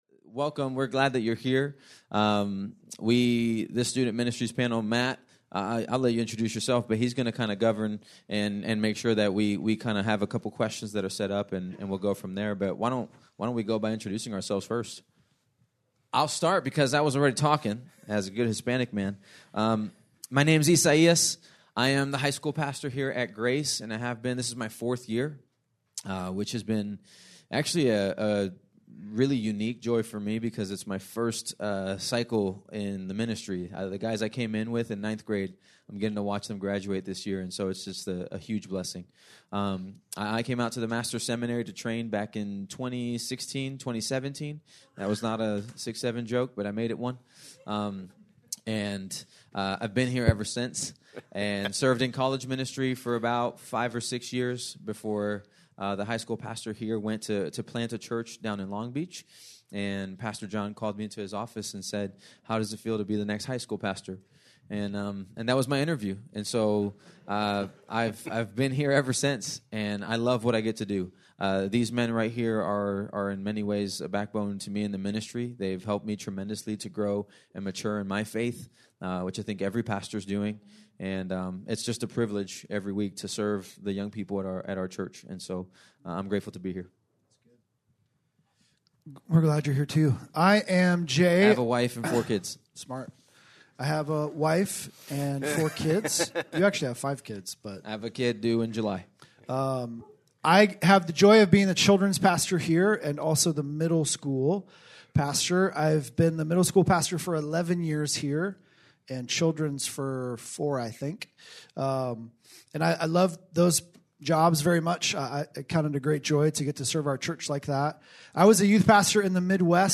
Student Ministries Panel Discussion